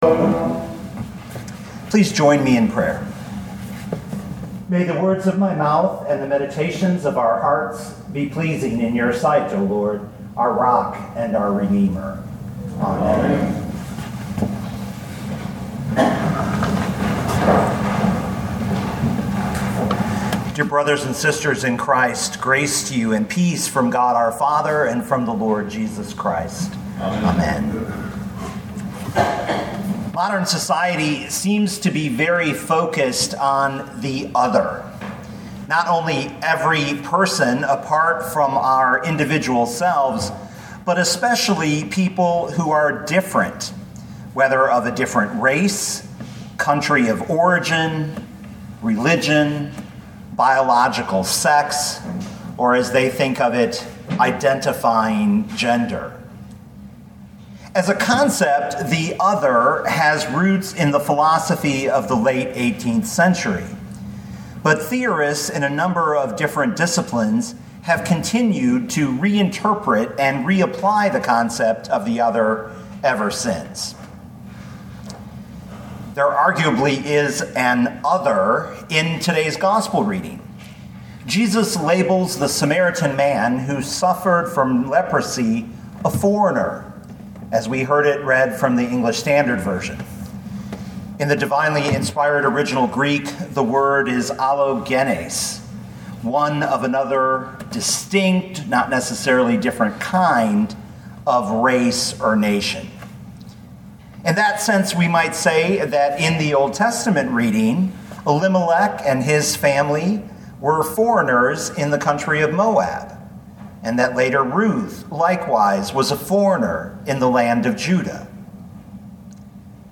2019 Luke 17:11-19 Listen to the sermon with the player below, or, download the audio.